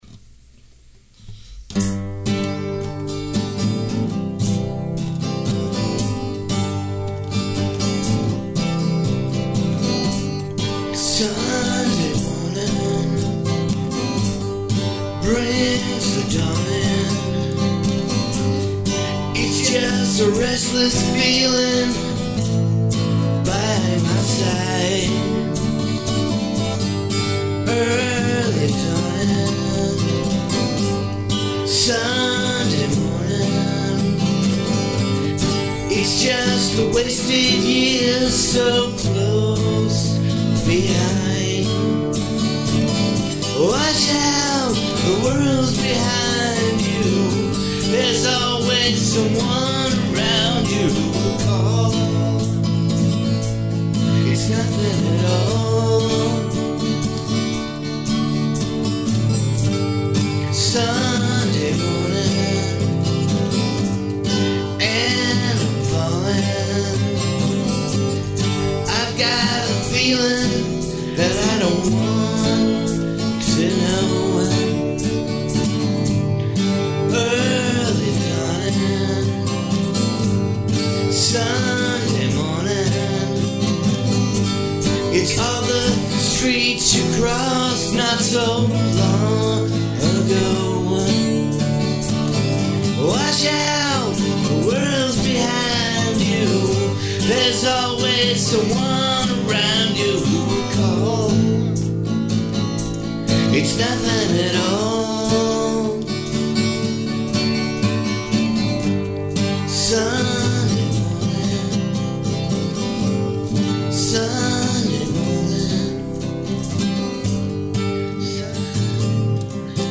I have always felt that this was a really peaceful song.